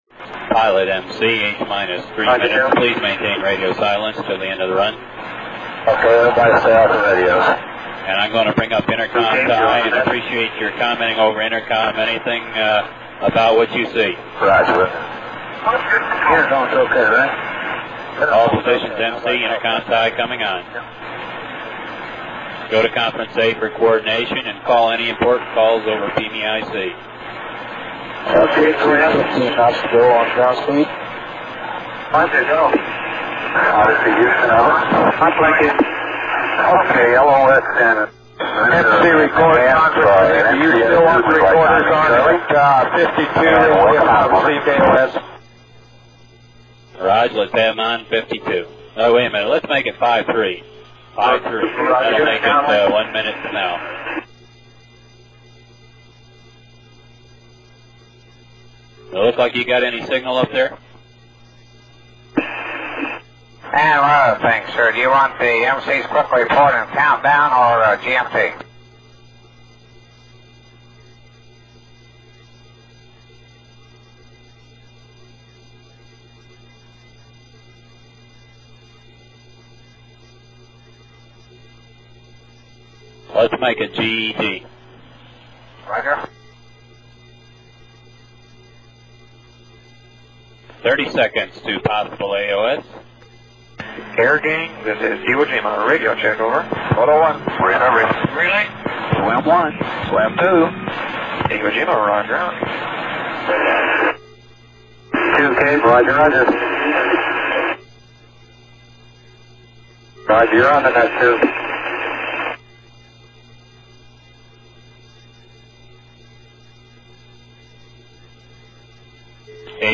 33:32 - approx. 20 second break in recording . 52:35 Iwo Jima Tower calls the recovery helicopters.